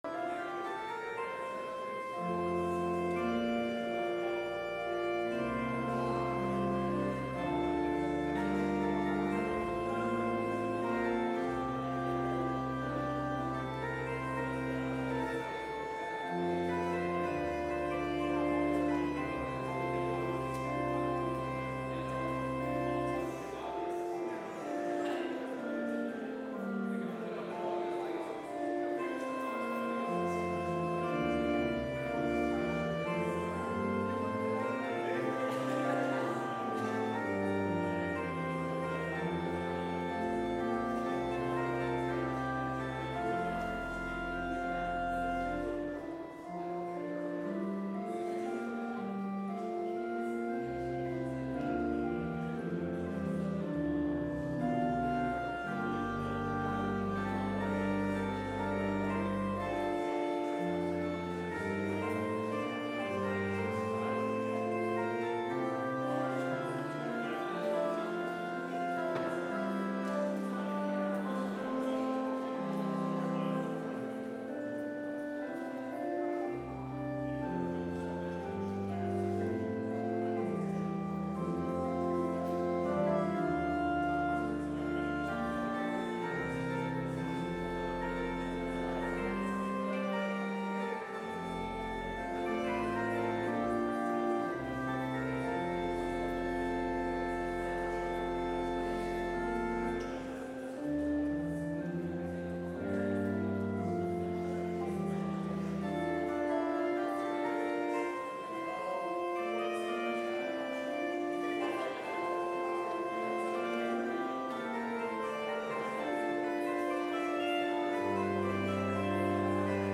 Complete service audio for Chapel - Tuesday, May 7, 2024